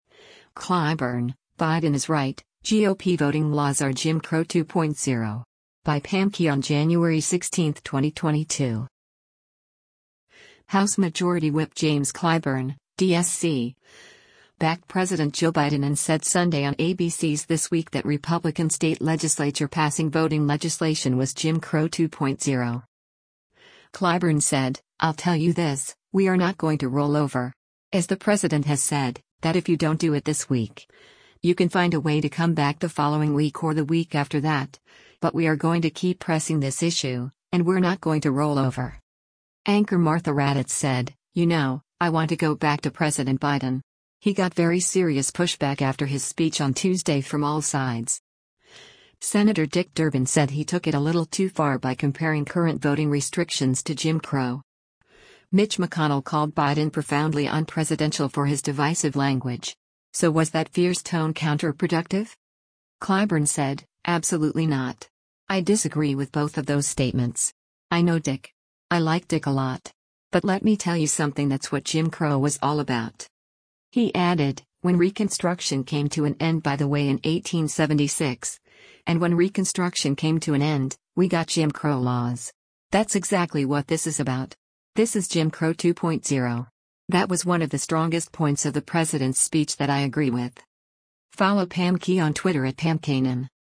House Majority Whip James Clyburn (D-SC) backed President Joe Biden and said Sunday on ABC’s “This Week” that Republican state legislature passing voting legislation was “Jim Crow 2.0.”